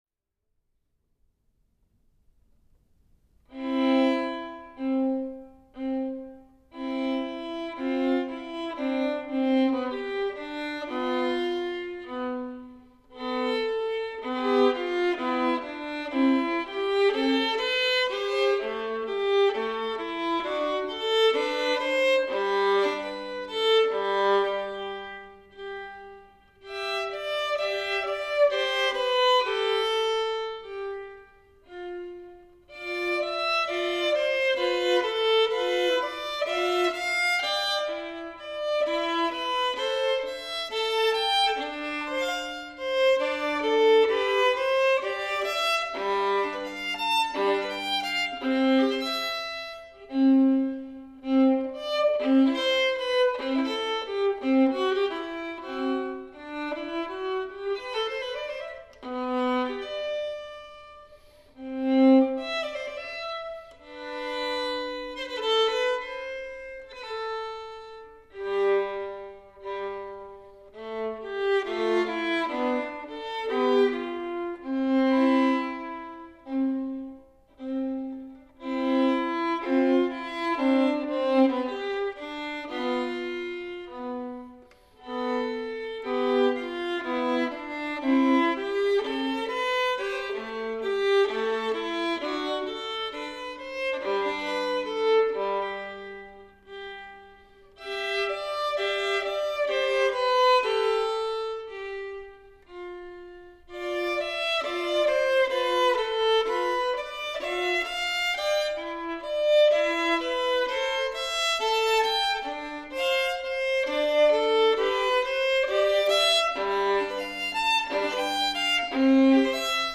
Peter Sheppard Skaerved – Violin (Stradivari 1698 ‘Joachim’)
St John the Baptist Aldbury-a perfect acoustic for Bach (for me, anyway)
Here are some outtakes-rough and unedited.
A-minor-Largo.mp3